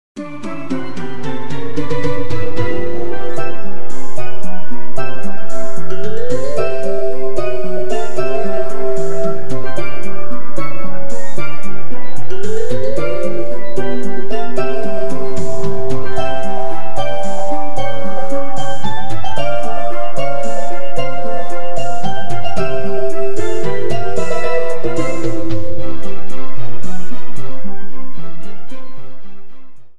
Trimmed and fadeout